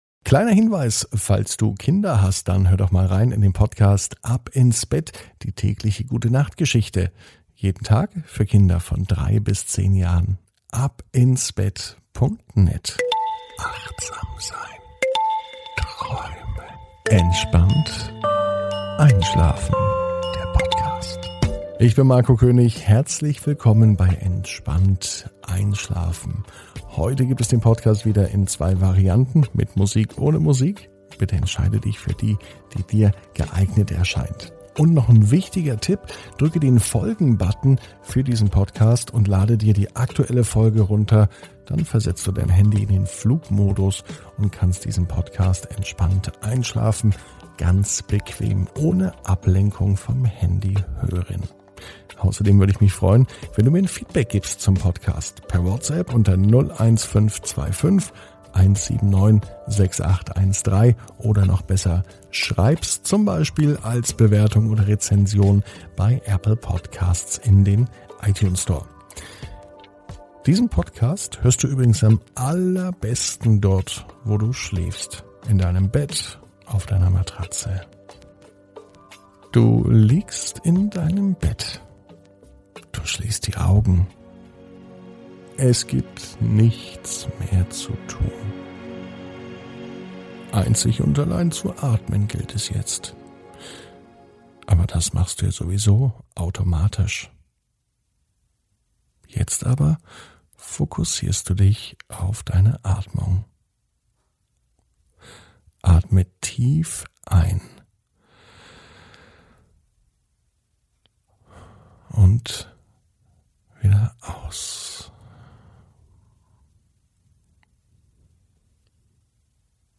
(ohne Musik) Entspannt einschlafen am Freitag, 07.05.21 ~ Entspannt einschlafen - Meditation & Achtsamkeit für die Nacht Podcast